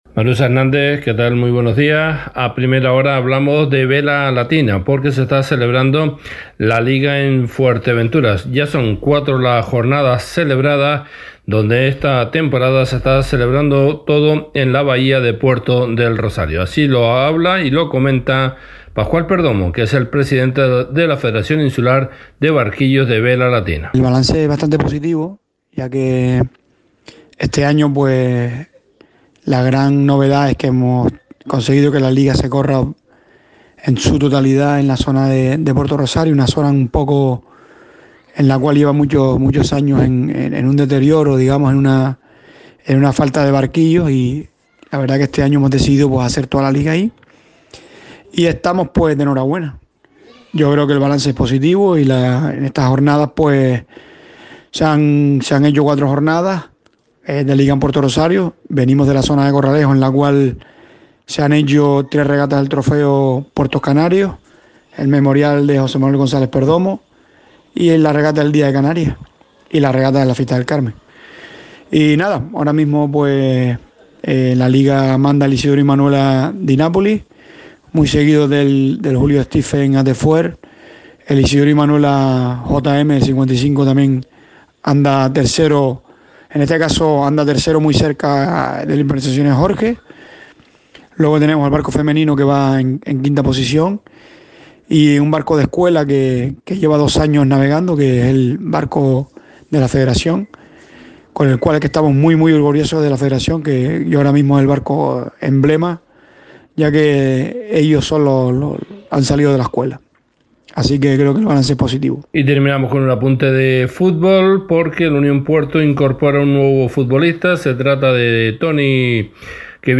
A Primera Hora, crónica deportiva